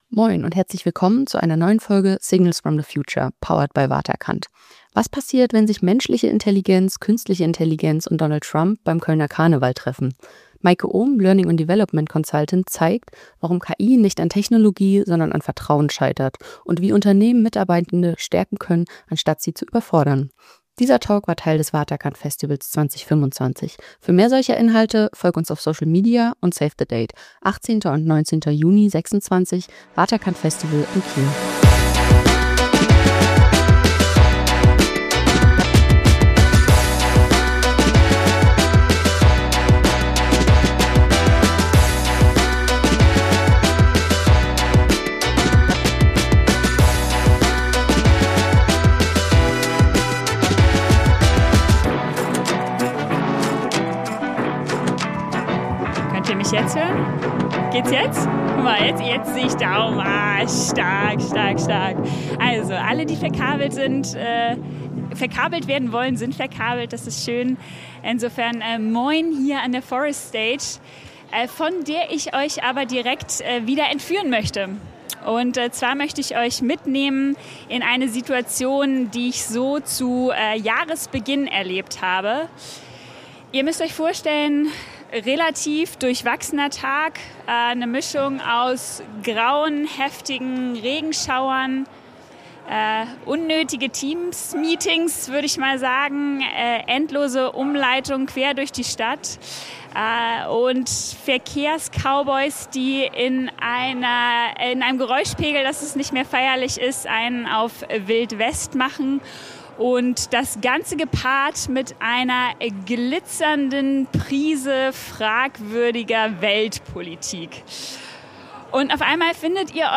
live vom Waterkant Festival.